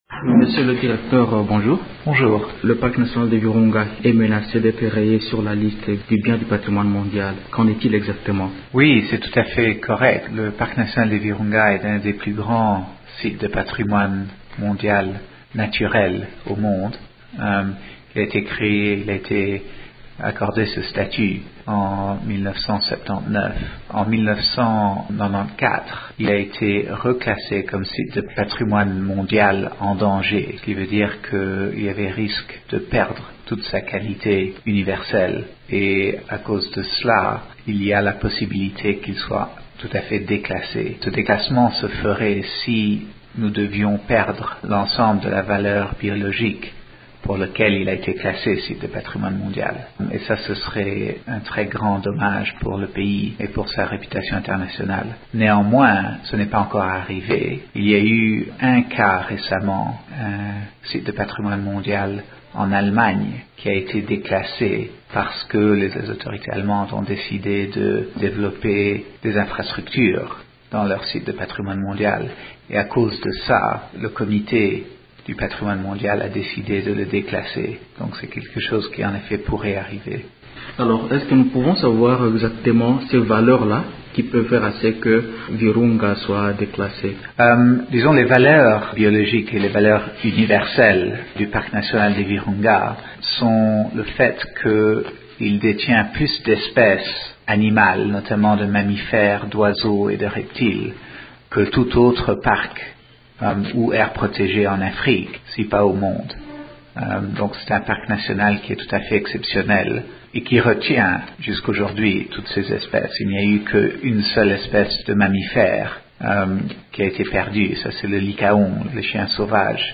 Nous évoquons ce problème avec notre invité du jour, le Directeur provincial de l’Institut Congolais pour la Conservation de la Nature, ICCN/ Nord-Kivu, Emmanuel De Merode.